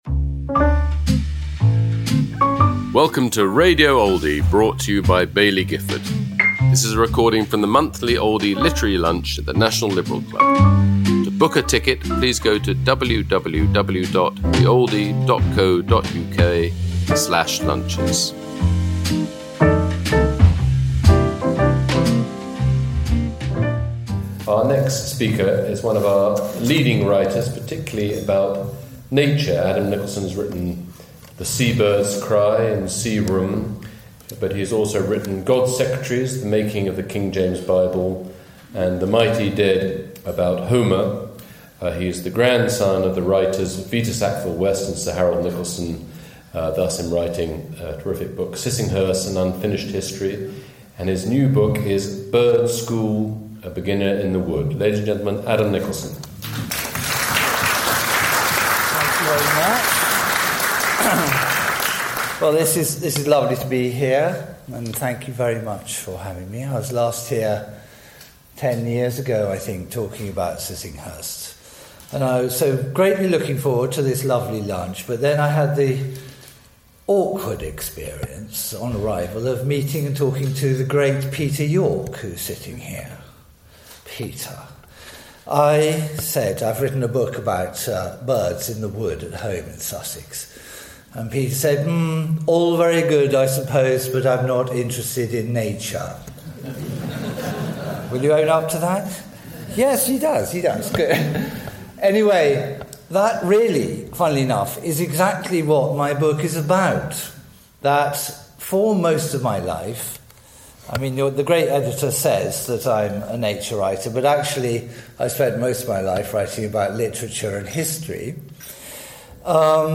Adam Nicolson speaking about his new book, Bird School: A Beginner in the Wood, at the Oldie Literary Lunch, held at London’s National Liberal Club, on May 6th 2025.